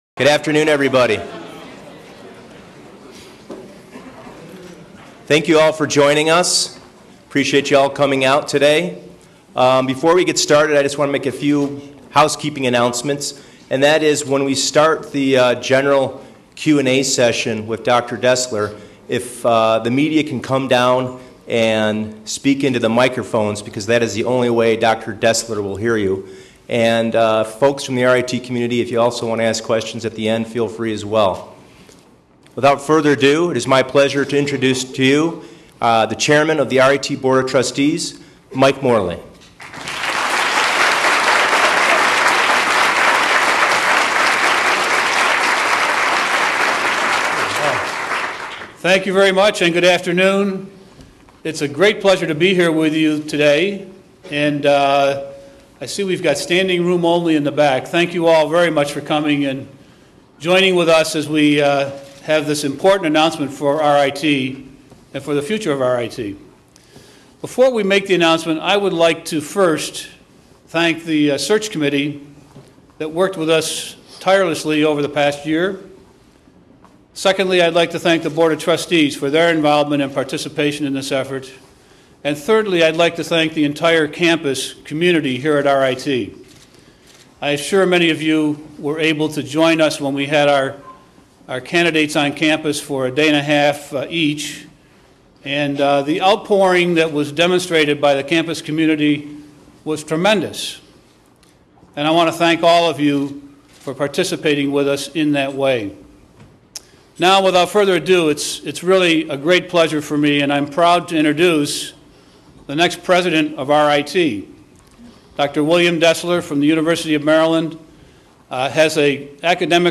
Audio podcast of March 5 news conference